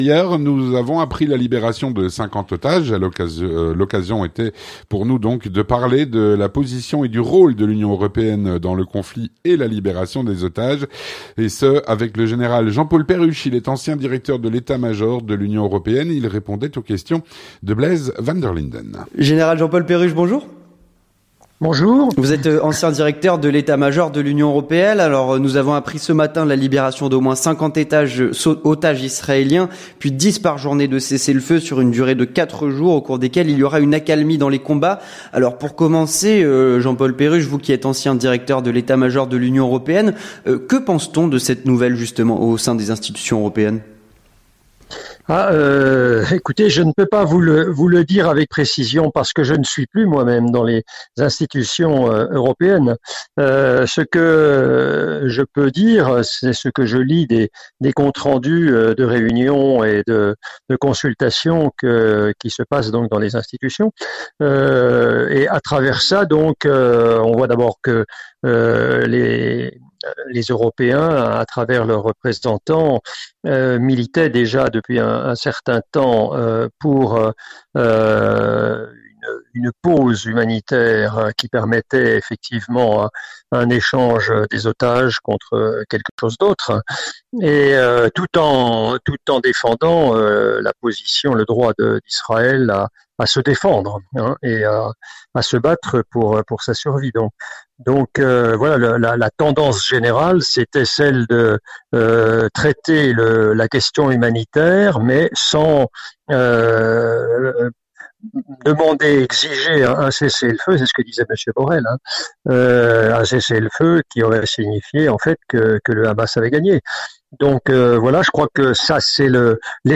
L'entretien du 18H - Le rôle de l'U.E. dans l'accord pour la libération des otages.